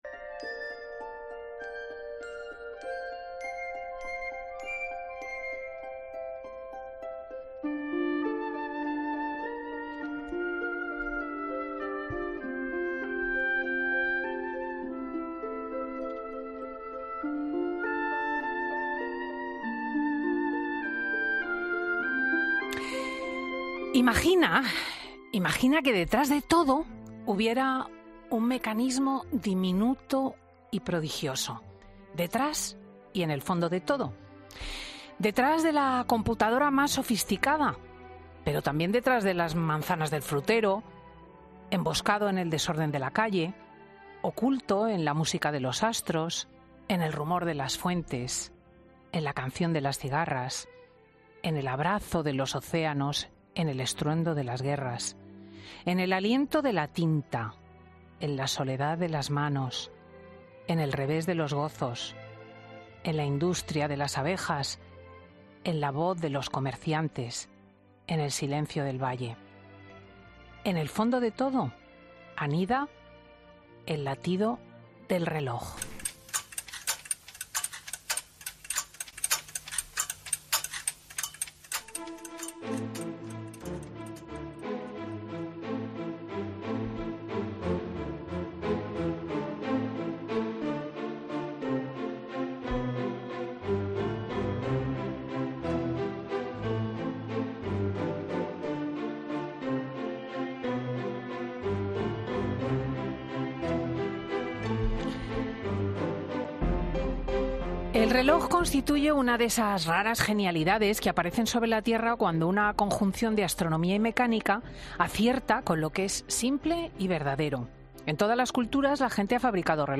Así ha comenzado Cristina López Schlichting la entrevista a Nacho Cano, un artista que precisamente "aprendió a dar cuerda a la rueda de las melodías universales y que puso en hora a toda una generación".